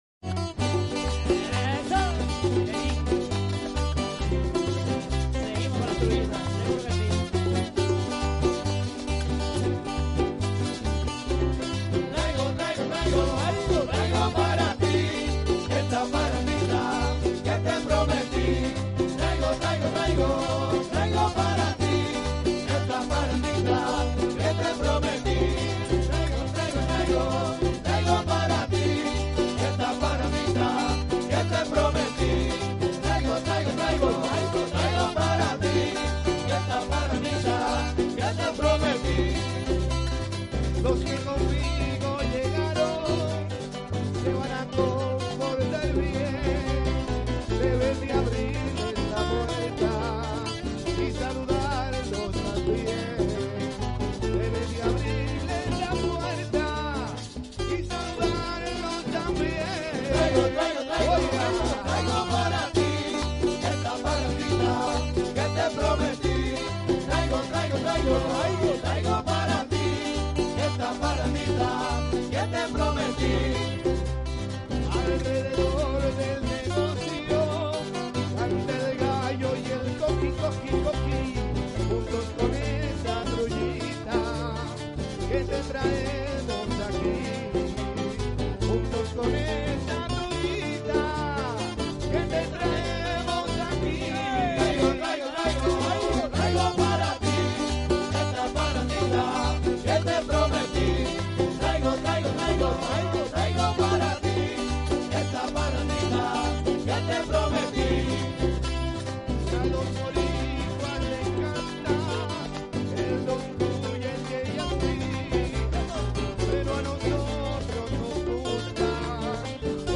Unos de los dias de nuestras vacaciones en Puerto Rico tuvimos la dicha de ir a una Parranda del grupo "Son de Aquí" y simplemente queríamos compartir con ustedes un poco de lo brutal que la pasamos con la autenticidad que los caracteriza.